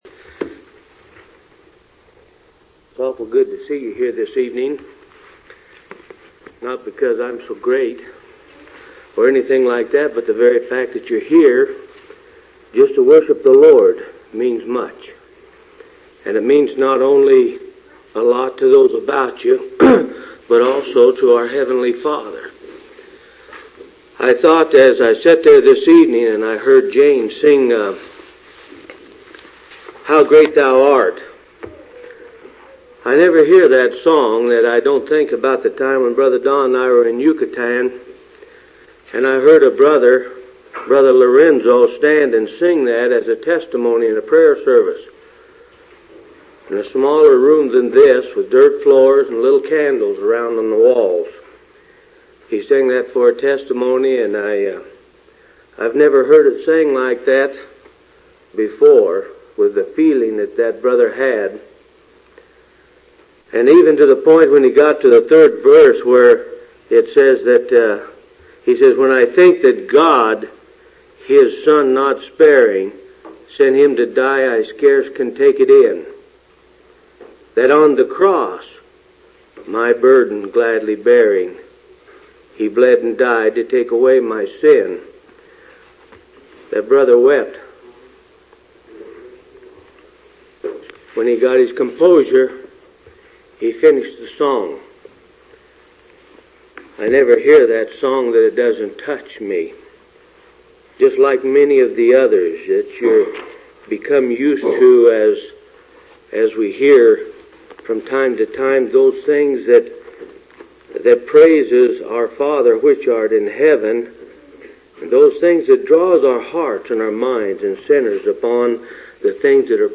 3/27/1983 Location: Phoenix Local Event